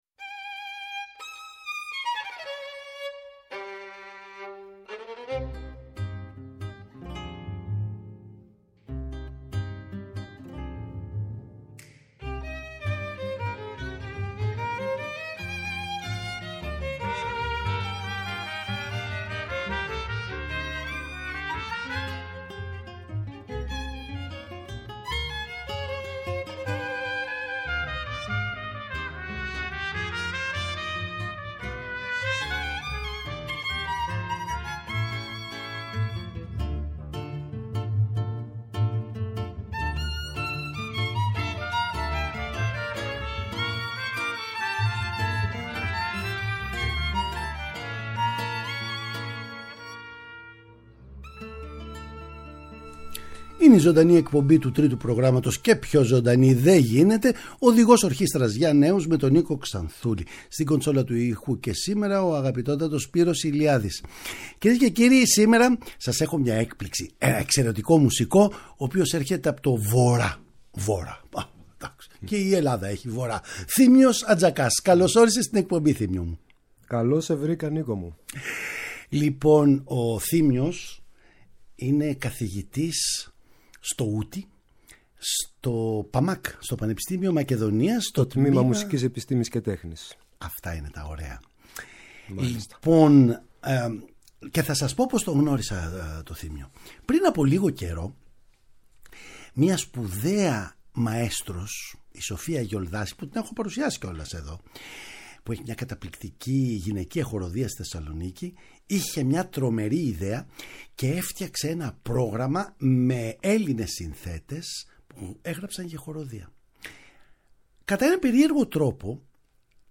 Με το ούτι του ανά χείρας θα μας πλανέψει σε χώρες μαγικές, εκεί όπου τά ρόδινα μάγουλα των κοριτσιών συναγωνίζονται με το ρουμπινί των ρόδων. Μουσικές που έπαιξε στην πορεία των χρόνων και αυτοσχεδιασμοί που μπορεί να προκύψουν ενώπιον του μικροφώνου. Δεν θα τον αφήσω μόνο του, η αρχαιοελληνική μου λύρα θα «διαλεχθεί» με έναν κόσμο που τη σεβάστηκε και υπηρέτησε την παγκόσμια αρμονία με θάρρος και επιμονή.
Παραγωγή-Παρουσίαση: Νίκος Ξανθούλης